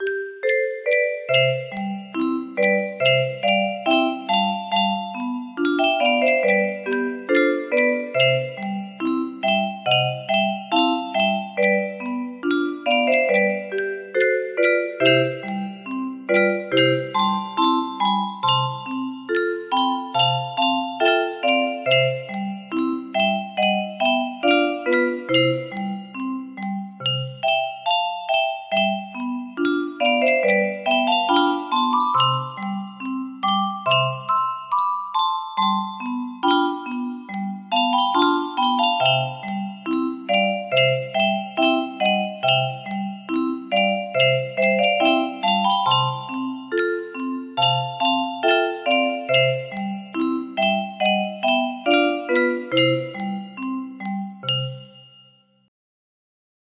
Narciso Serradell - La Golondrina music box melody